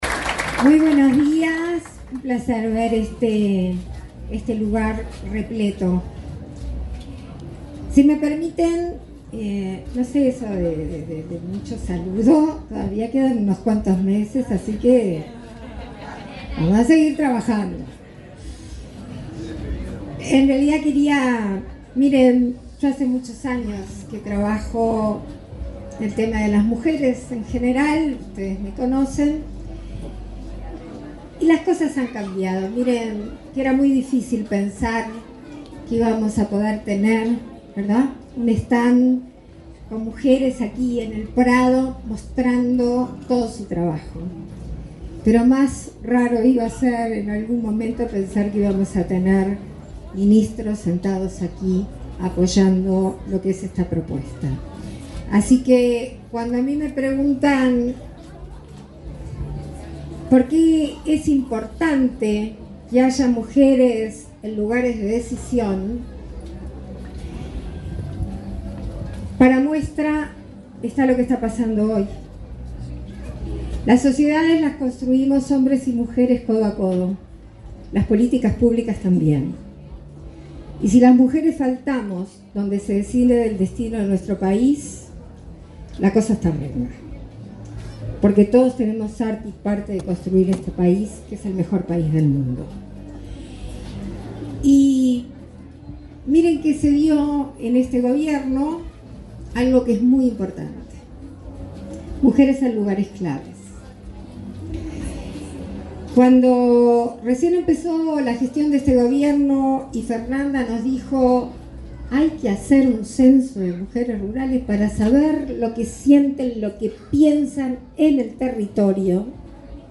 Palabras de la presidenta en ejercicio, Beatriz Argimón
La presidenta de la República en ejercicio, Beatriz Argimón, participó en la tercera edición del evento Diálogos y Saberes: Mujeres Rurales y del Agro